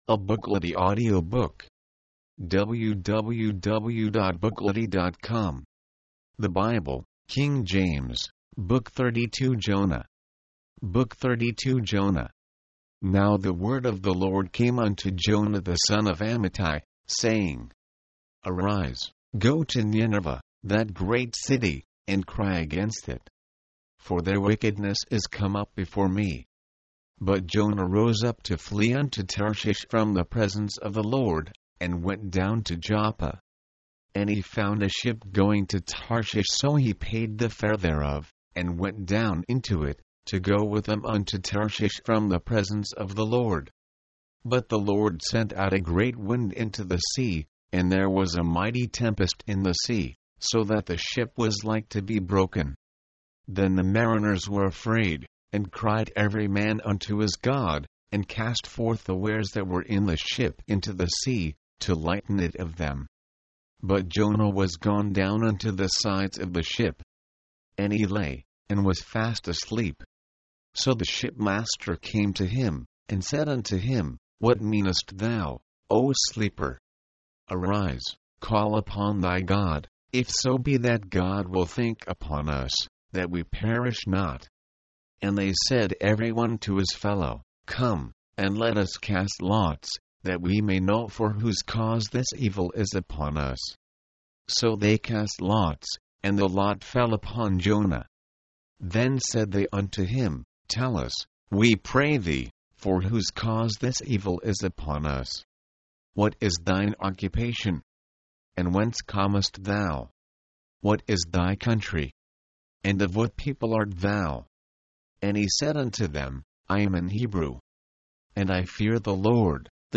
The source for Free Audio Books, Mp3 Bible, Find the Song, new books, used books, school books, videos, music, movies and consumer media.